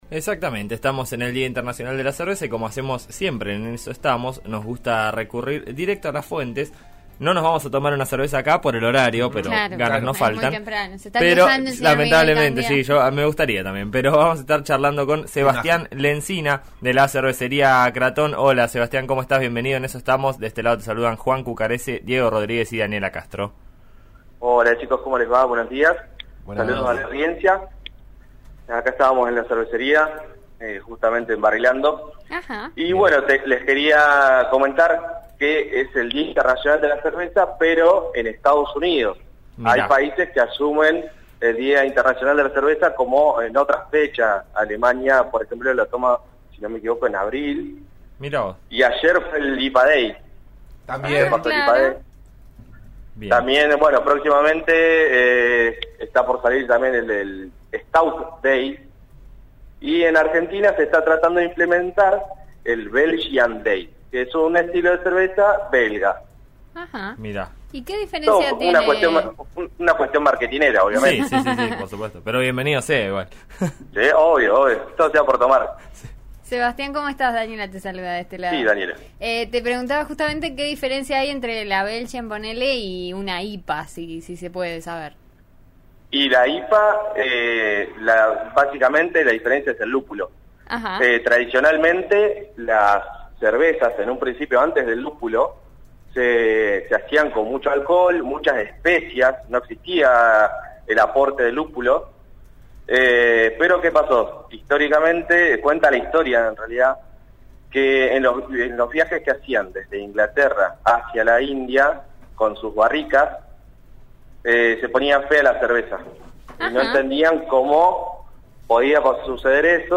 En eso estamos de RN Radio habló con un cervecero roquense